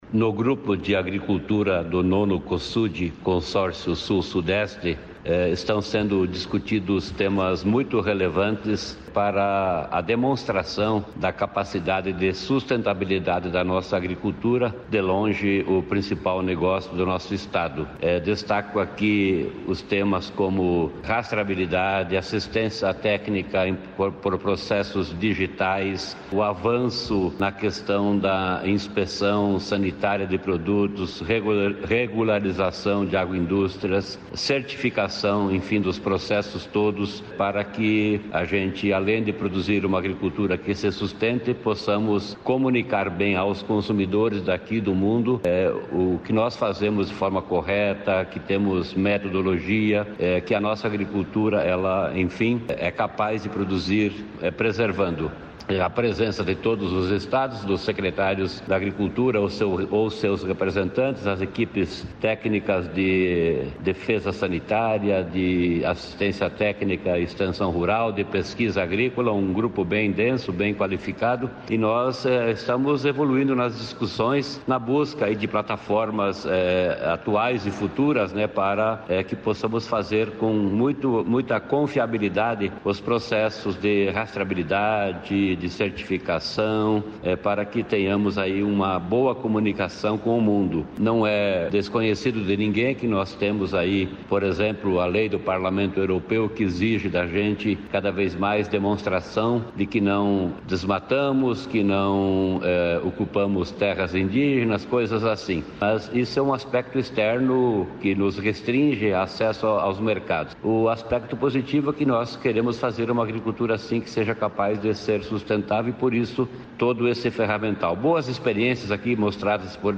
Sonora do secretário da Agriculta e do Abastecimento, Norberto Ortigara, sobre a reunião de secretários da agricultura